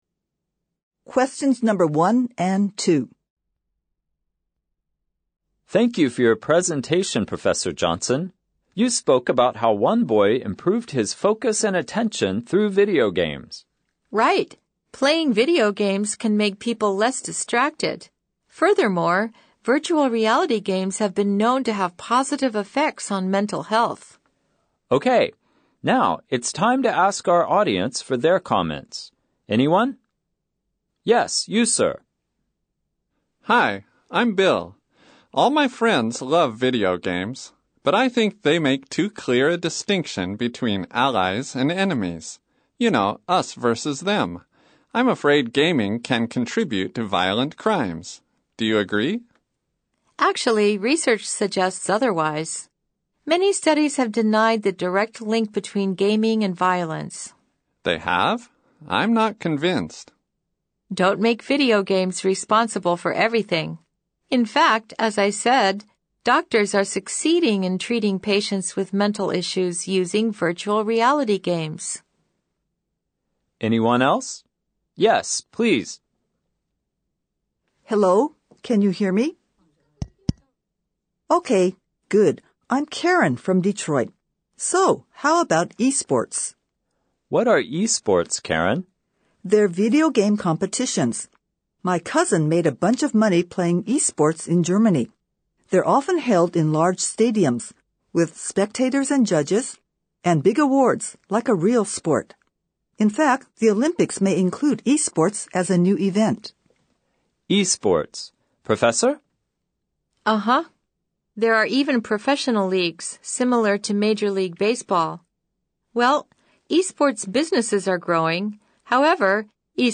大学入学共通テスト (施行調査) 145〜165wpm
【第6問B】 英語を聞き、問題用紙に記載されたれぞれの問の答えを選択肢から選ぶ問題 放送は1回のみ、4人の会話です。
オーディオブックの朗読が150～160wpmなので、「すごく早口！」というわけではありませんが、1回のみの放送の場合や、複数人の会話の場合もあるので、英語を聴くことに慣れておく必要があります。